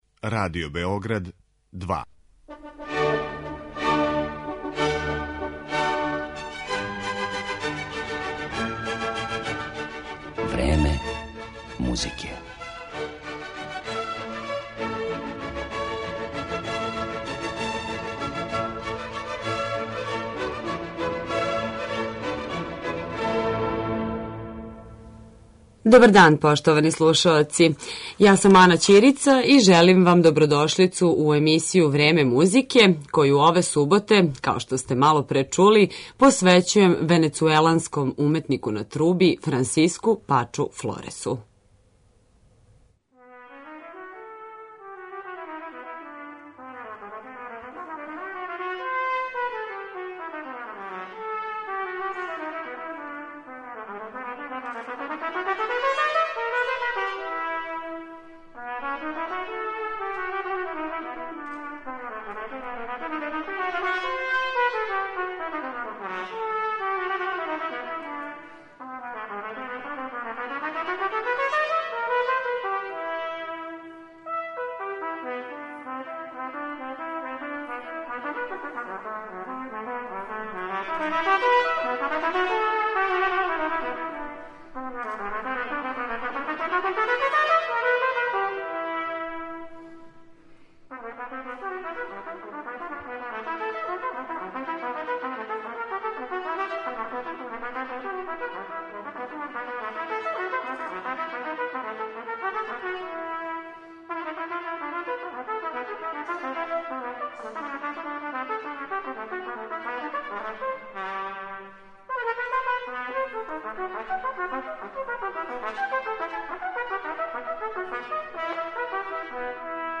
Представљамо вам трубача Франсиска Пача Флореса.